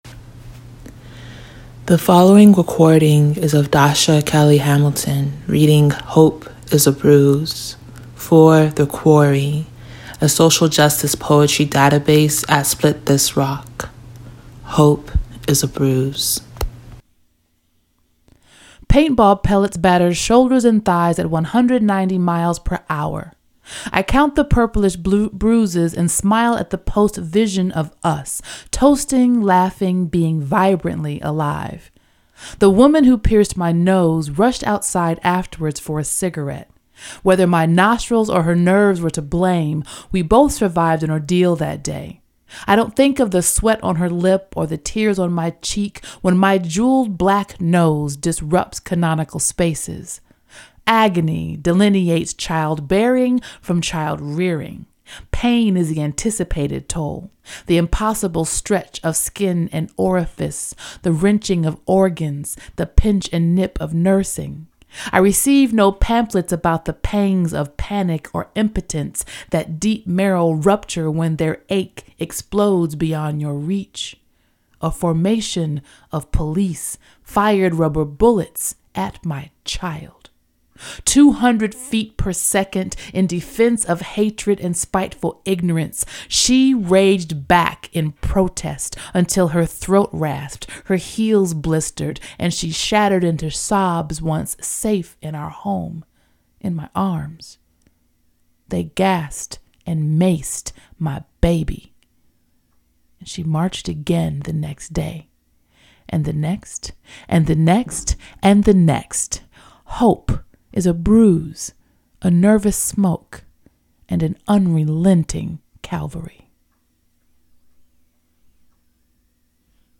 Listen as Dasha Kelly Hamilton reads ""Hope is a Bruise"."